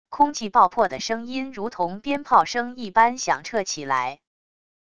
空气爆破的声音如同鞭炮声一般响彻起来wav音频